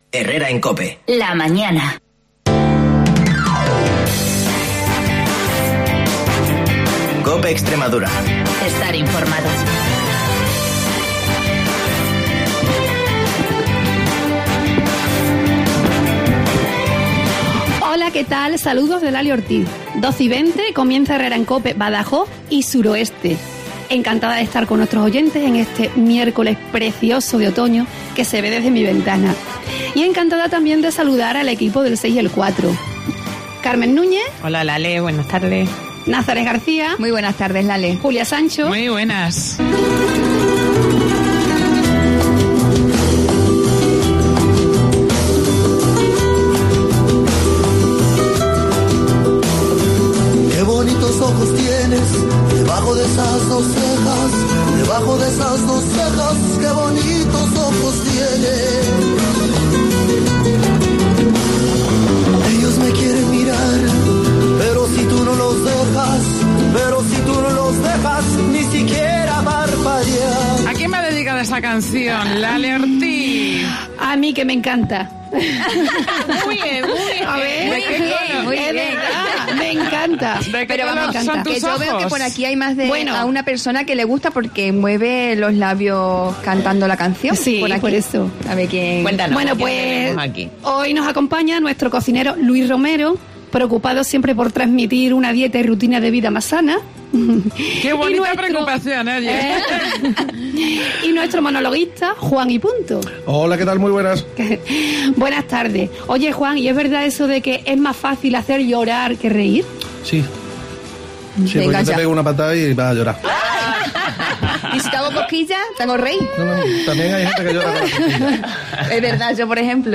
Gastronomía, cocina, humor, remedios caseros, risas y muy buen rollo hoy